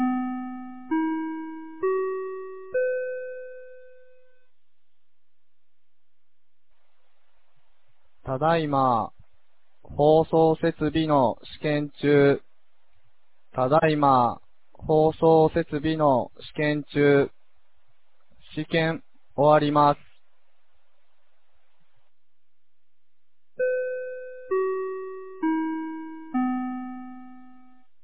2019年08月03日 16時05分に、由良町より全地区へ放送がありました。